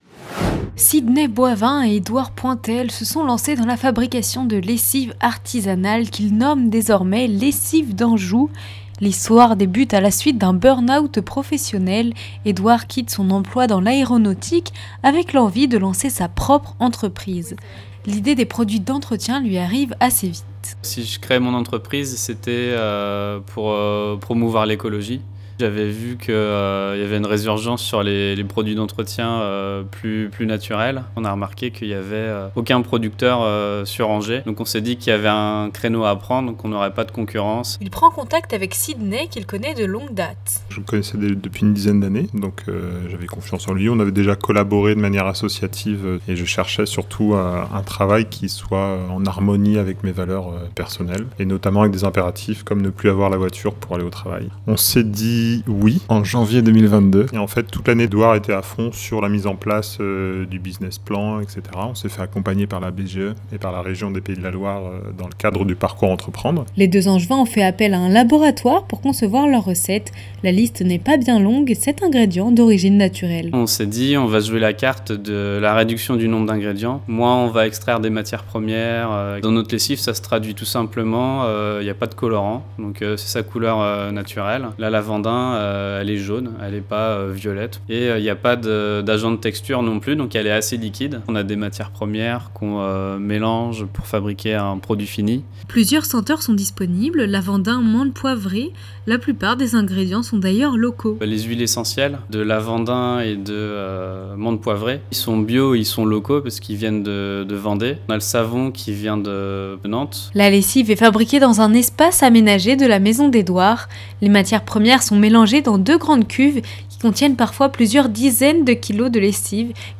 reportage_sapocyclo_final.mp3